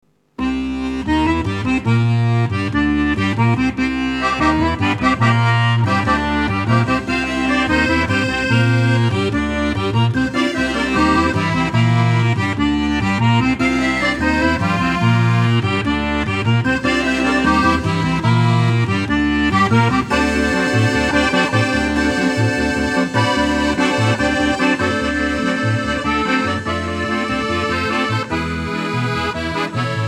Recorded at Stebbing Recording Studios